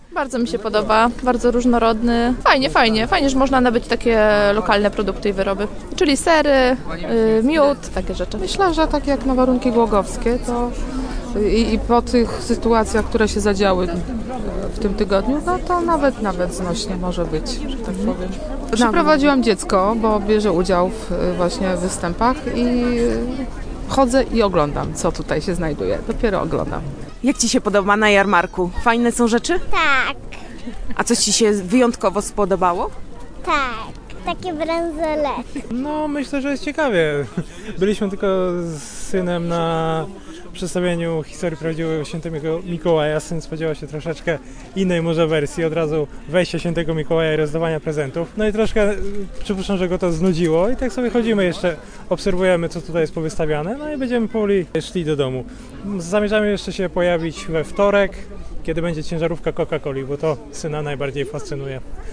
A jak mieszkańcom podoba się jarmark świąteczny? Posłuchaj:
1204_jarmark_sonda.mp3